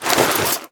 tac_gear_7.ogg